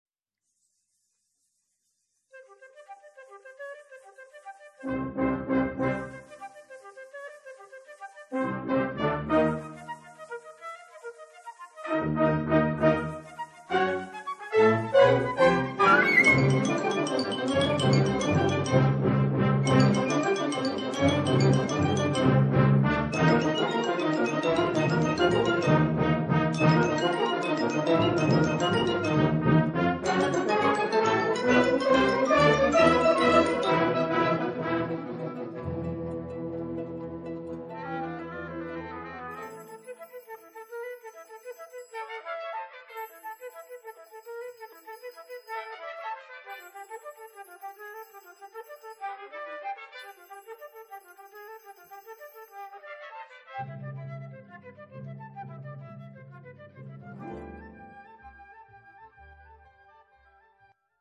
Concerto pour Flûte et Orchestre d'Harmonie